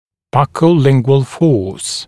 [ˌbʌkəu’lɪŋgwəl fɔːs][ˌбакоу’лингуэл фо:с]сила, действующая в щечно-язычном направлении